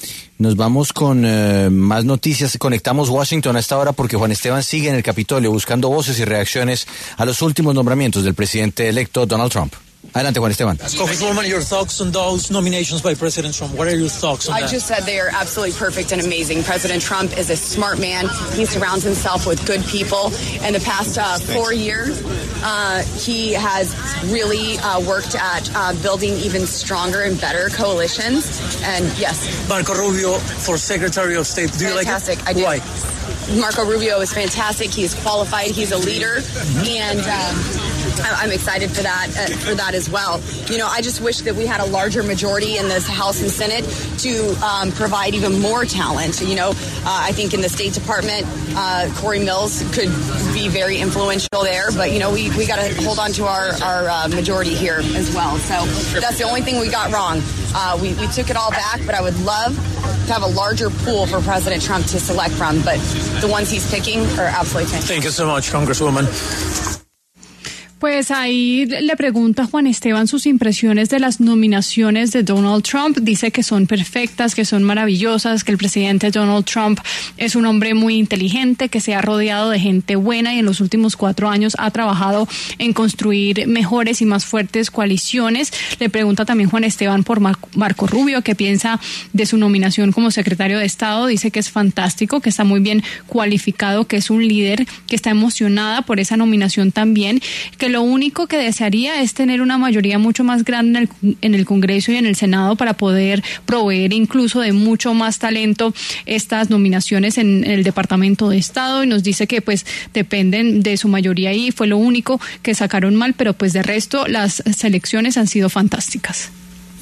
Lauren Boebert, congresista republicana por Colorado, pasó por los micrófonos de La W con Julio Sánchez Cristo y habló sobre los últimos nombramientos por parte del presidente electo de Estados Unidos, Donald Trump, para su gabinete presidencial.